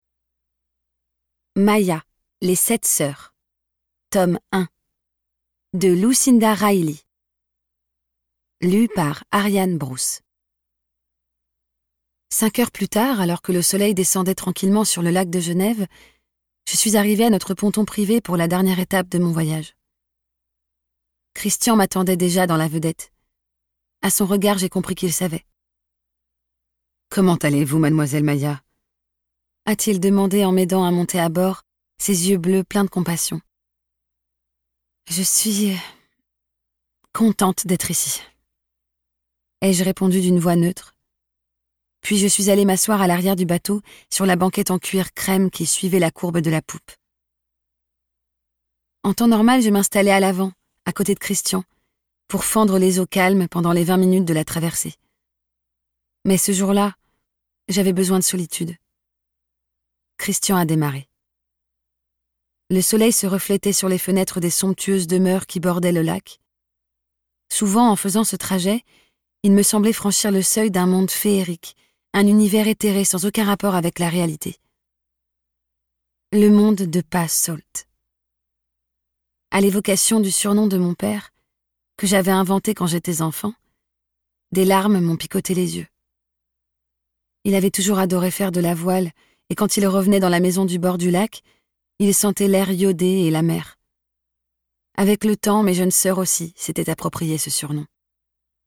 Livres audio pour Adultes